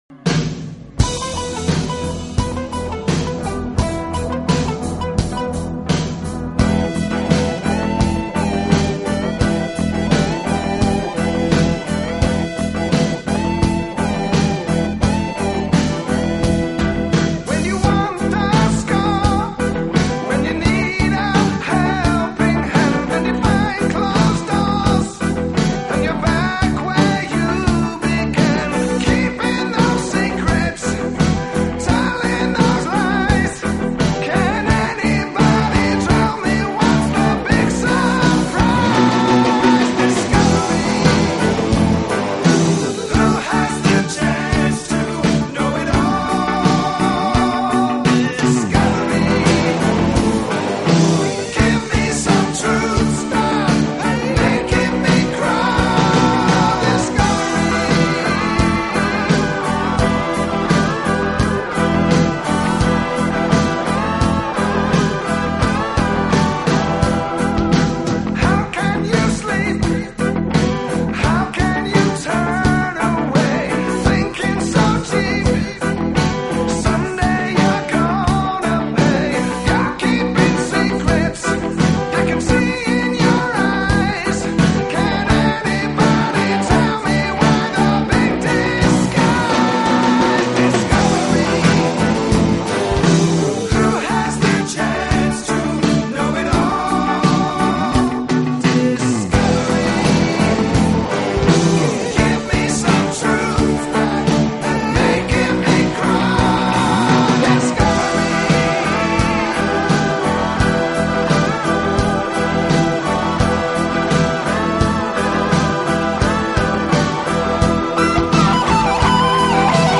新世纪音乐